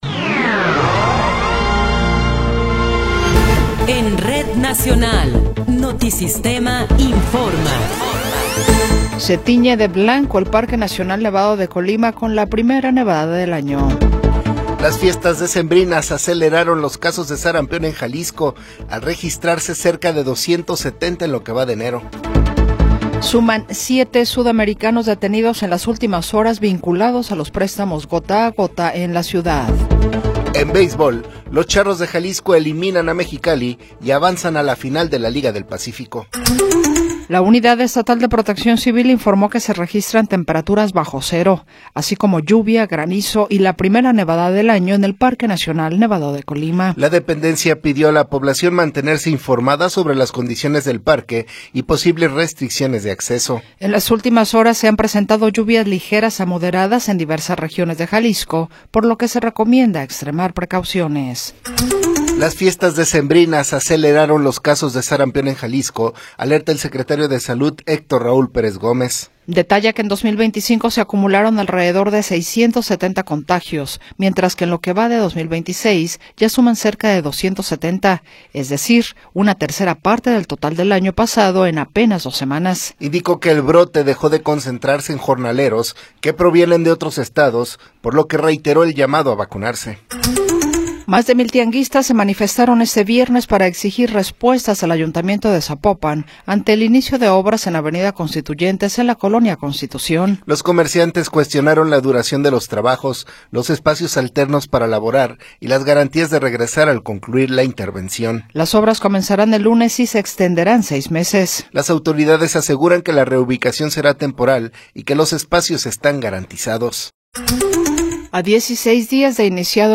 Noticiero 9 hrs. – 17 de Enero de 2026
Resumen informativo Notisistema, la mejor y más completa información cada hora en la hora.